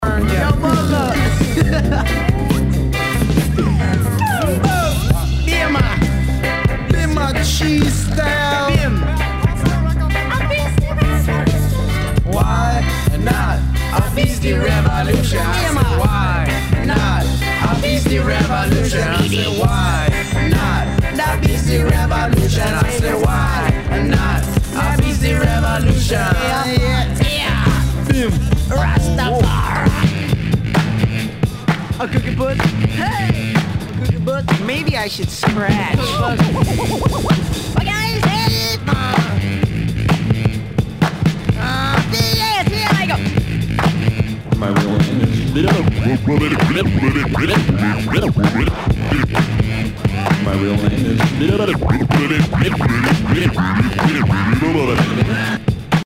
HIPHOP/R&B
ナイス！ラガ・ヒップホップ！